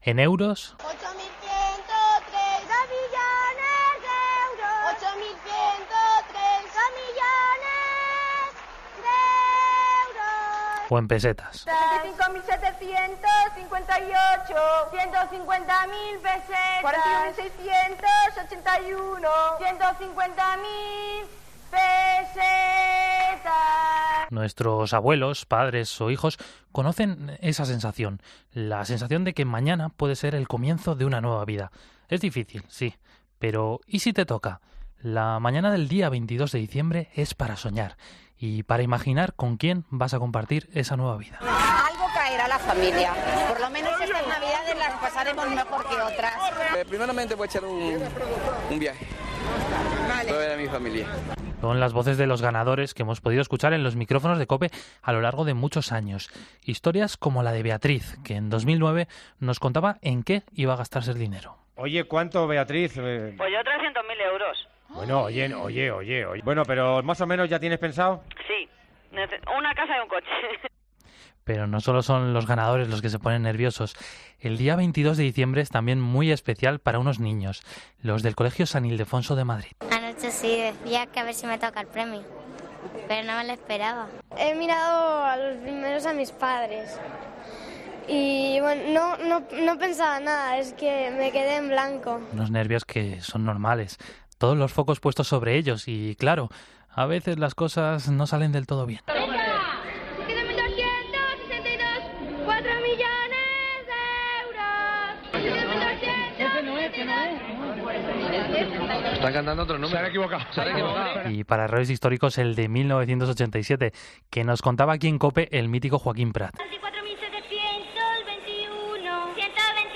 Repasamos los mejores momentos de los sorteos de la Lotería de Navidad en COPE